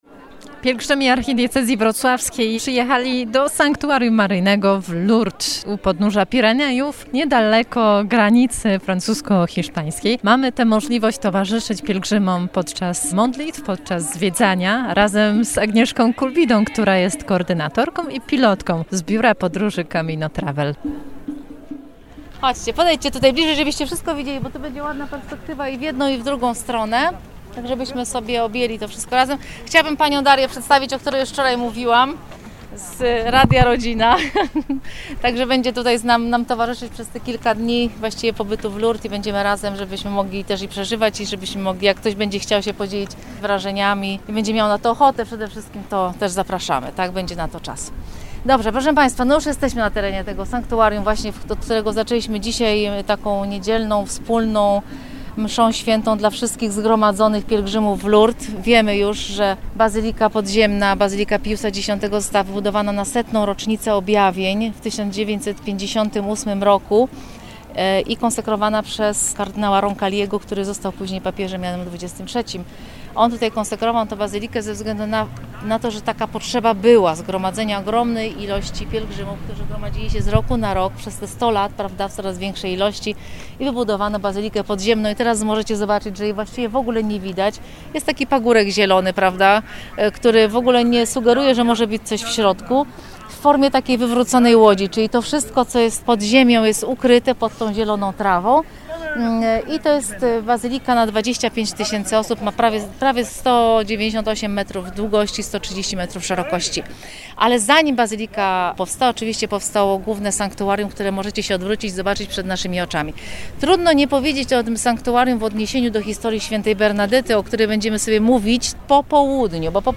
Przypominamy migawkę z pobytu w Lourdes razem z grupą pielgrzymów z parafii z Wójcic, którą odbyliśmy we wrześniu ub. roku.